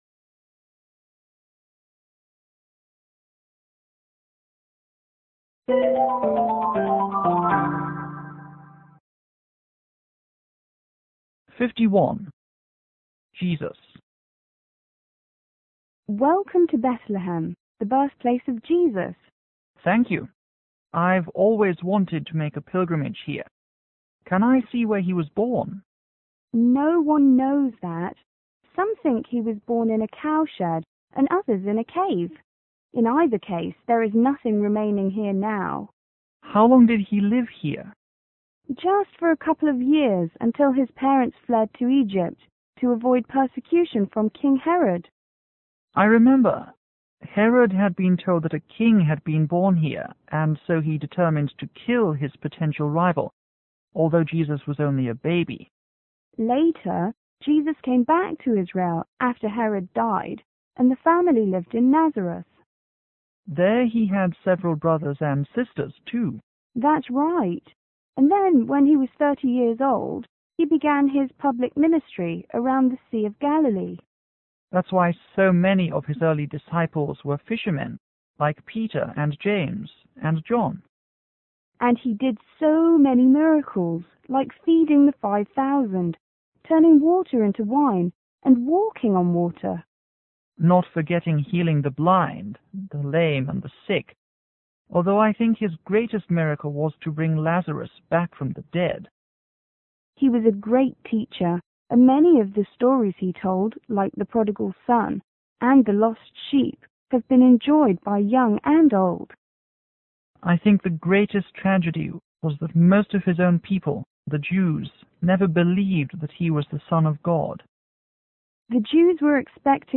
I: Israeli    C: Chrrstian